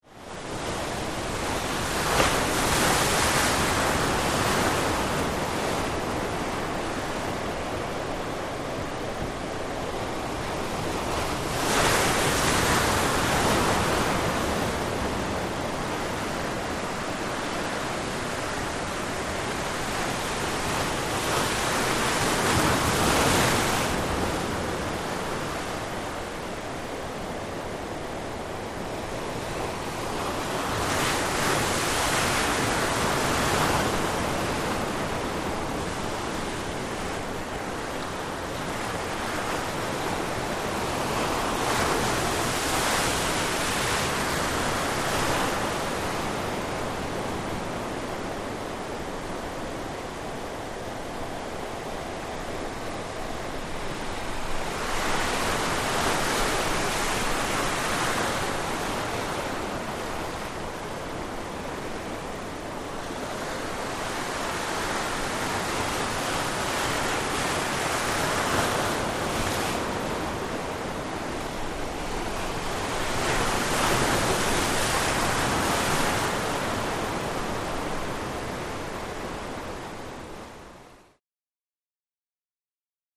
Wave Crashes
Close Up On Mediterranean Sea, Waves Break On Rocks, Some Splash.